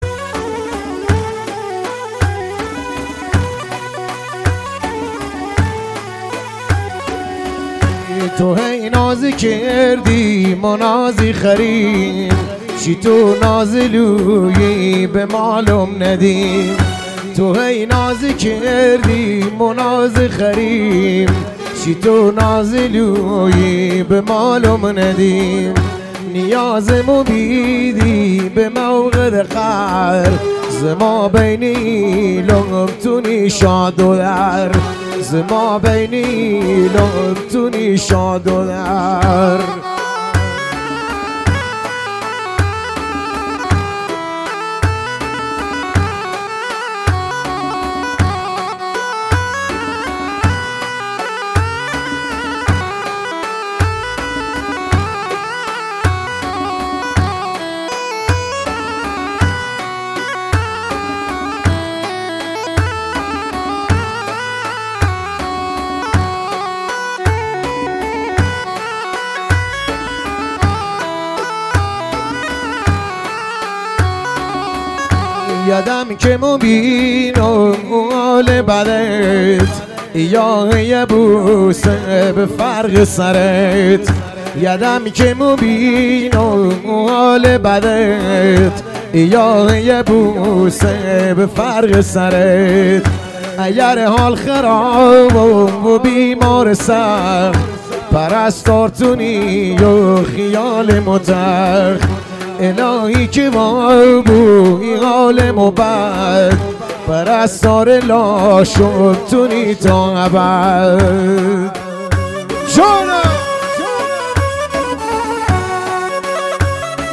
محلی عاشقانه لری